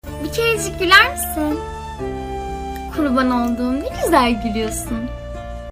bi kerecik guler misin 873737 Meme Sound Effect